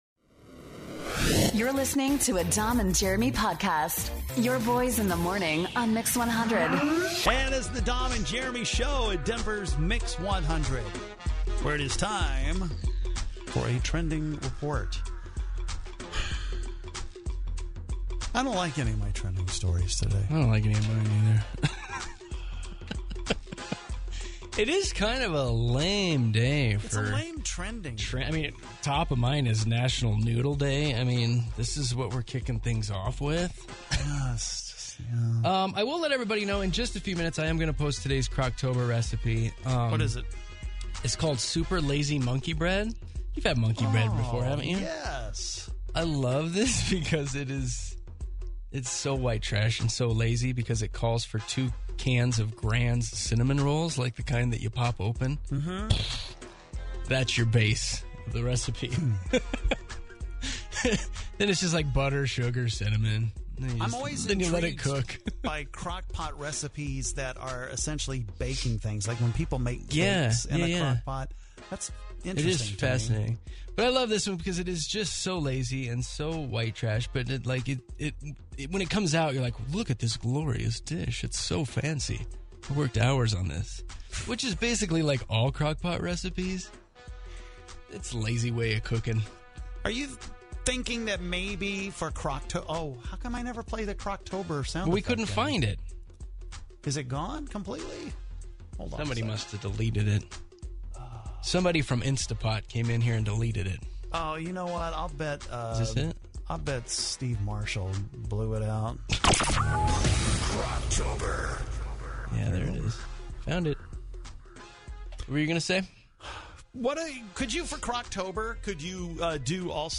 We chit chat back and forth talking about what is trending today...which is not a lot.